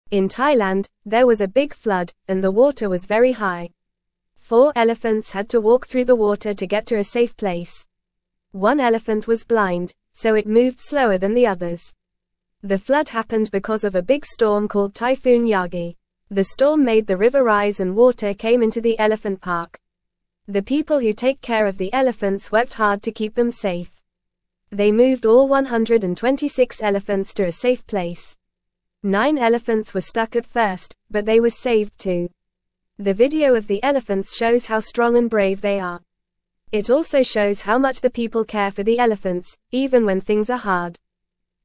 Fast
English-Newsroom-Beginner-FAST-Reading-Elephants-Brave-Flood-in-Thailand-People-Keep-Safe.mp3